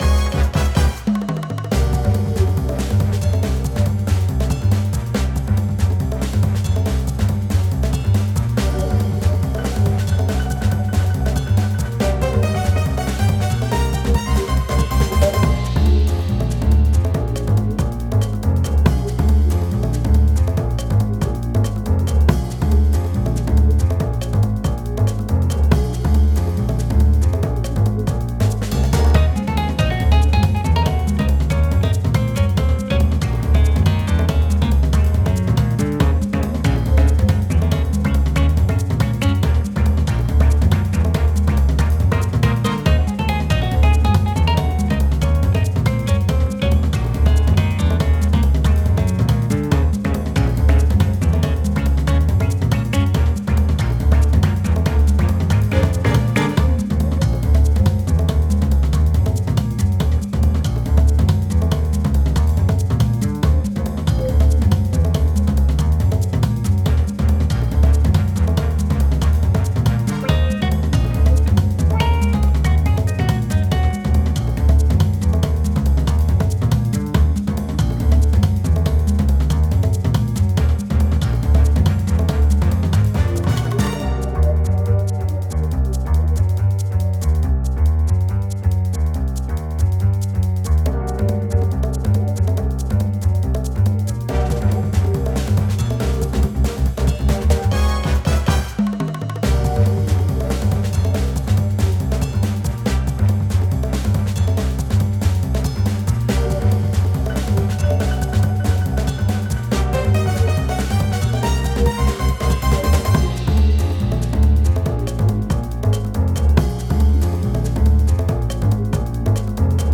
スパイ,ミッション,作戦,捜査,潜入などを思わせるBGMです。 結構無理やりループしちゃってます...